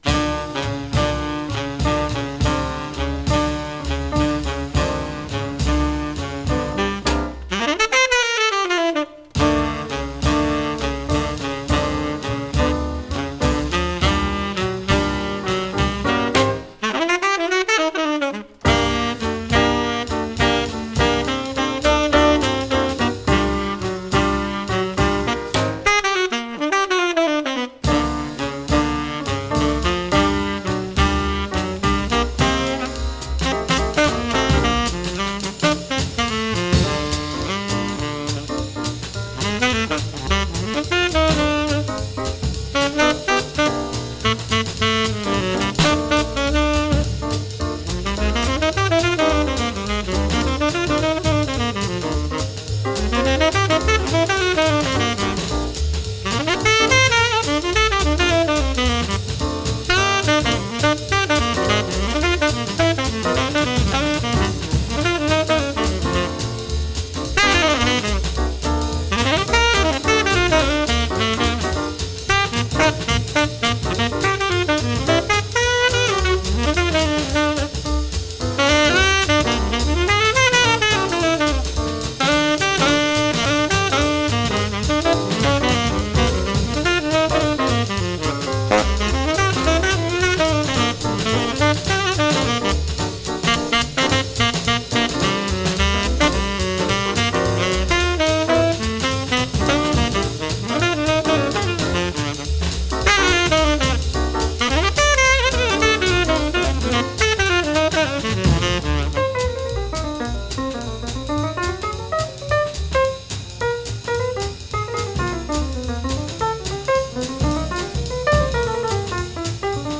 tenor saxophone